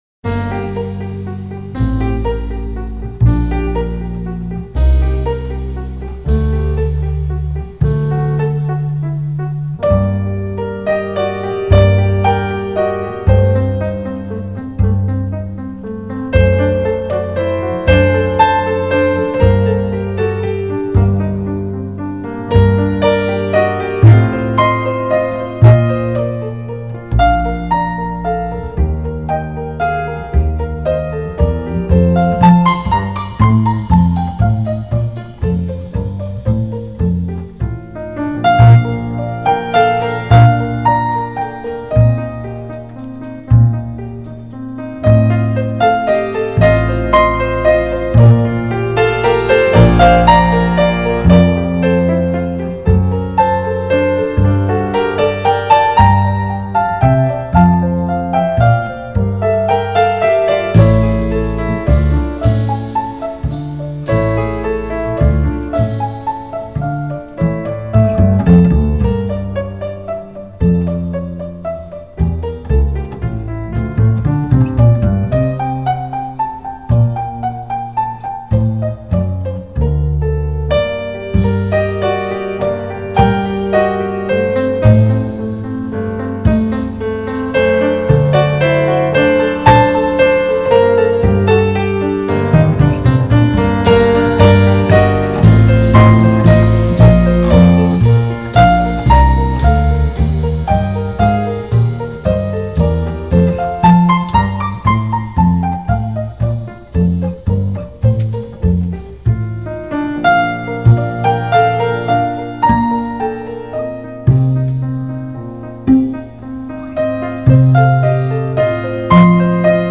Жанр: Classic|Relax